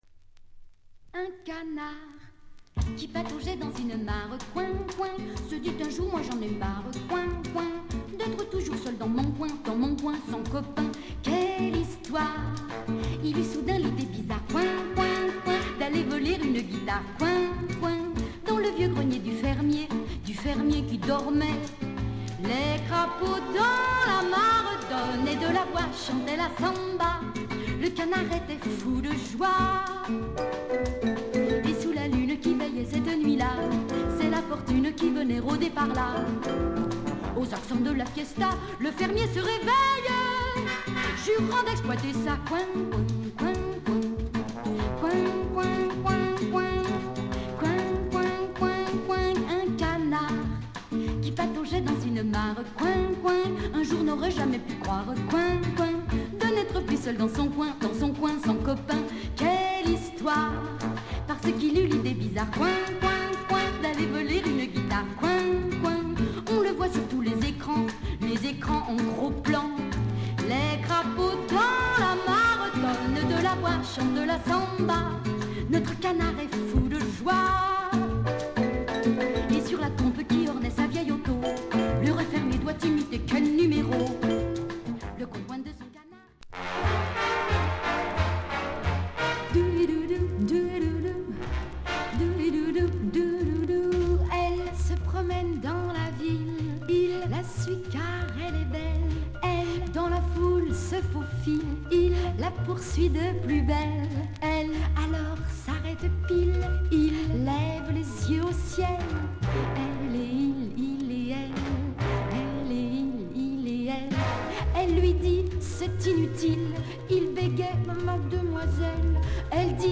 AメロでまったりしつつBメロでハッとさせられるタイトル曲♪